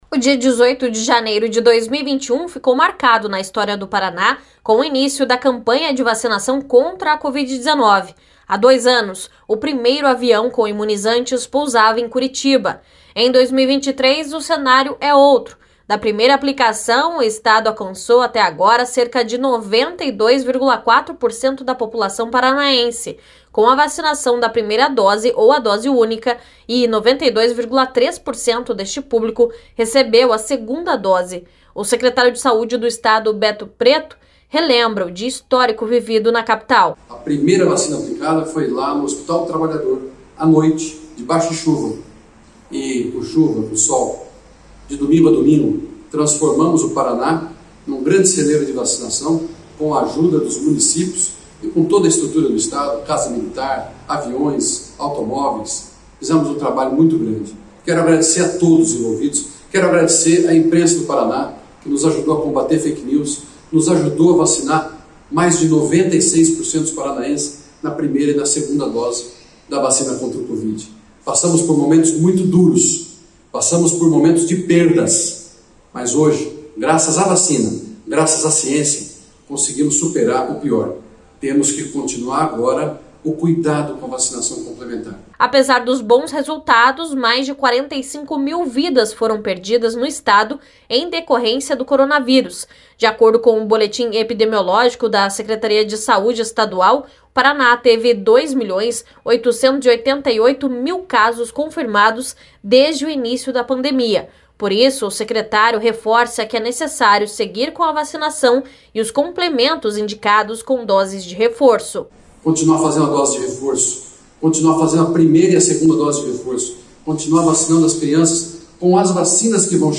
O secretário de saúde do estado, Beto Preto, relembra o dia histórico vivido em Curitiba.